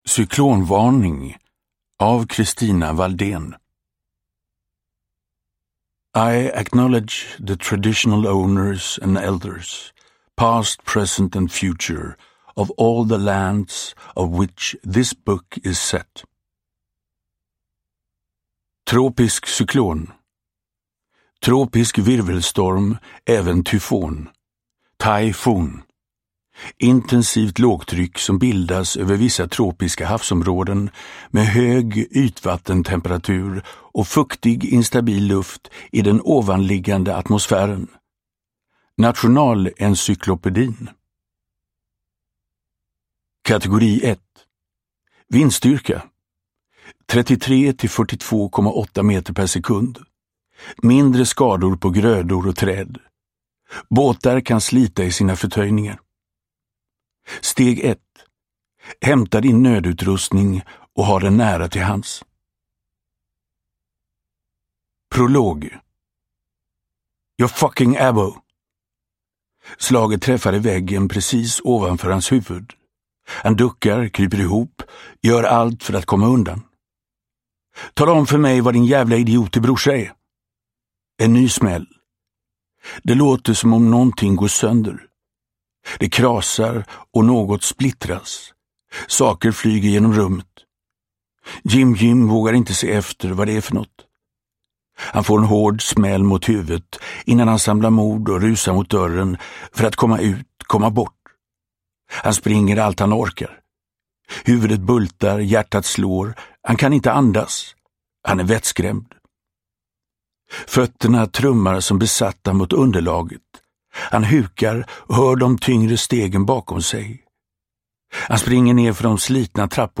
Cyklonvarning – Ljudbok – Laddas ner
Uppläsare: Magnus Roosmann